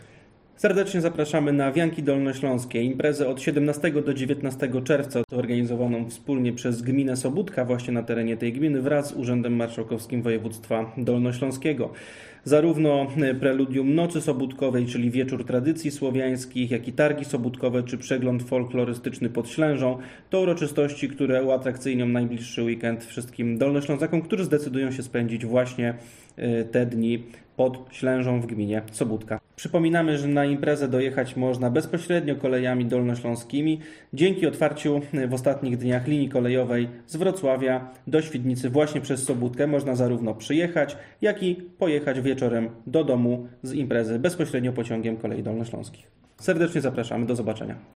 – Impreza wyjątkowa, do tego w chyba najlepszej możliwej lokalizacji – przeżyjmy wspólnie preludium Nocy Świętojańskiej pod Ślężą – zaprasza wicemarszałek Grzegorz Macko.
G.Macko-Zaproszenie-wianki-_1.mp3